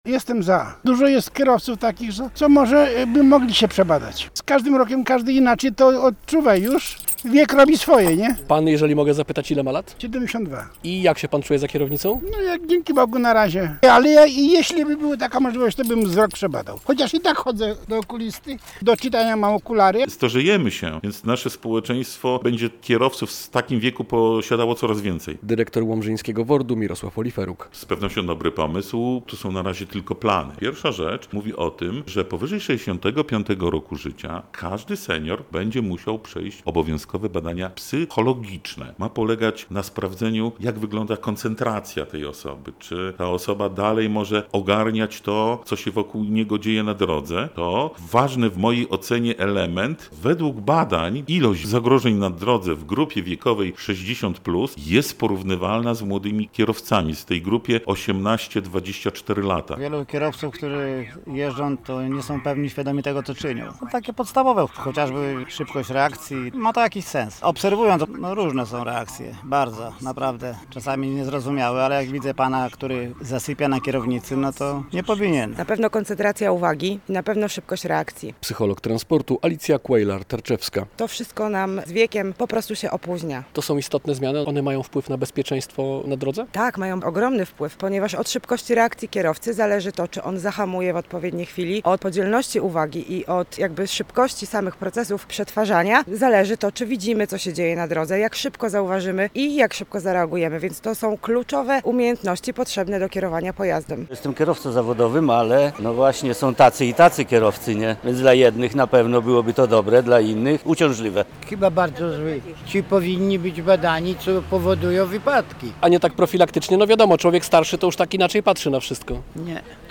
Większość kierowców, z którymi rozmawiał nasz reporter, uważa, że to dobry pomysł.